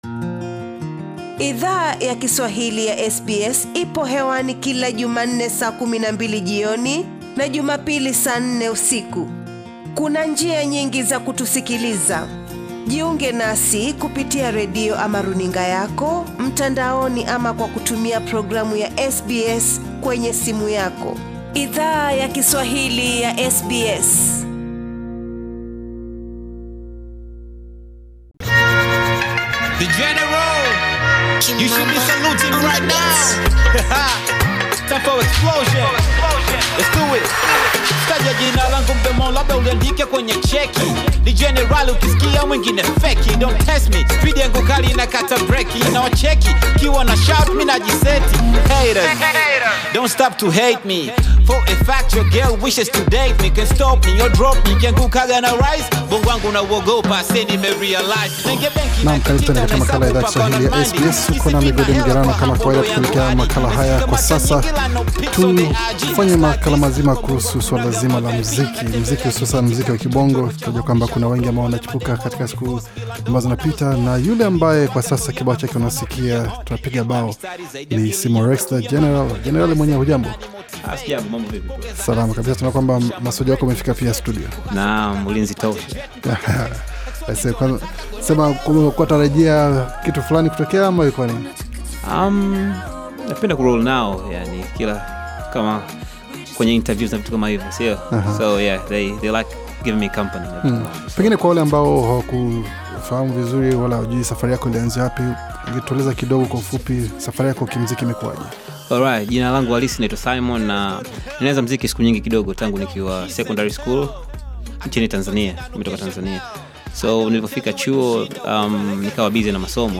ndani ya studio za SBS